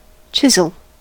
chisel: Wikimedia Commons US English Pronunciations
En-us-chisel.WAV